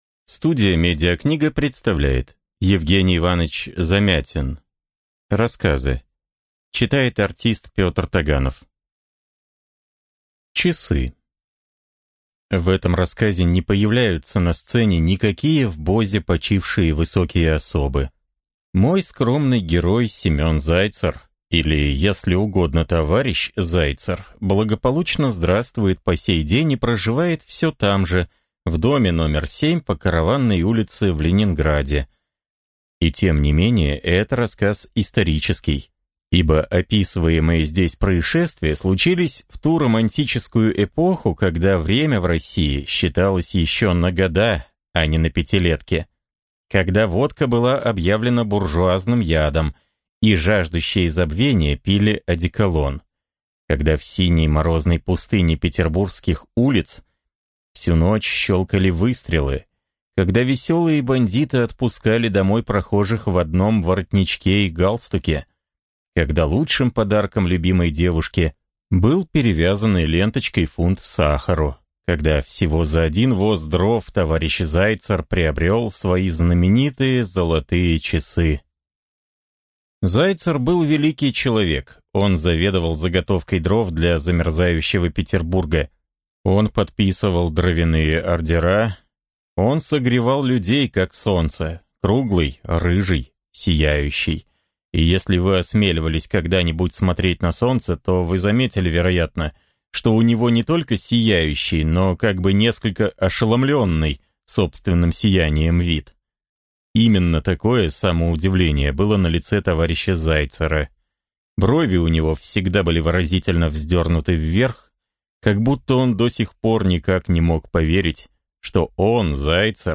Аудиокнига Часы. Лев. Встреча. Пещера. Дракон. Мамай. Дясетиминутная драма | Библиотека аудиокниг